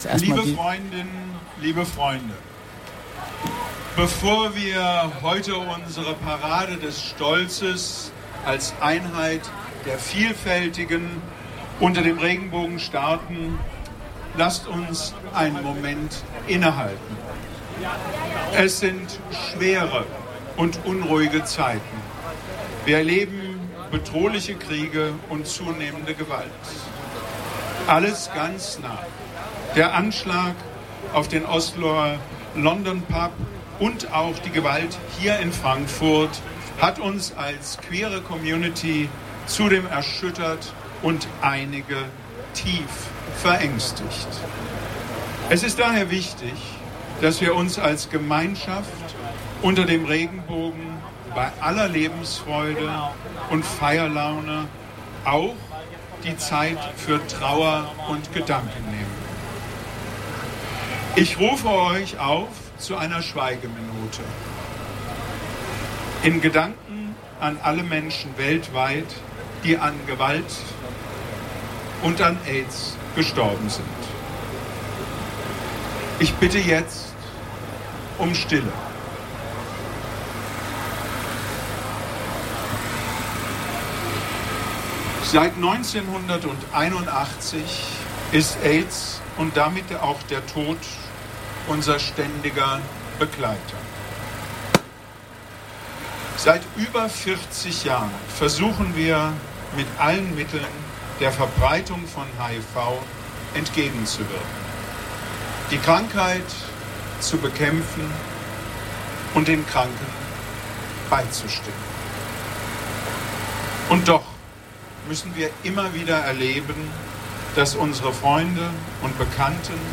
Die Schweigeminute auf dem Römerberg